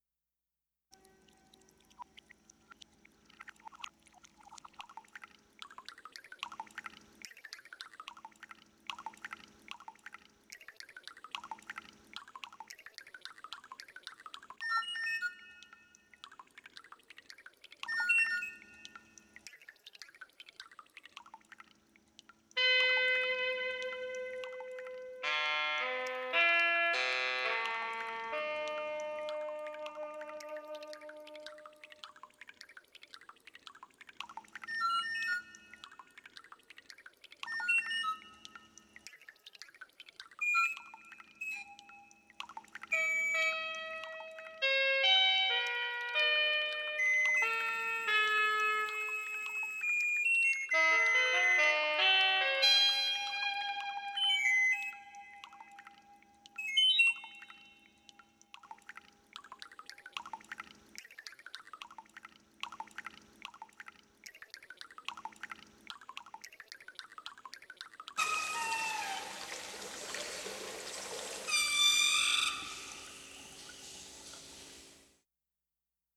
These then were processed and mixed on the computer.